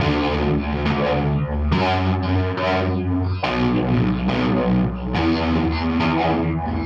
tx_synth_140_pod_CGbBE.wav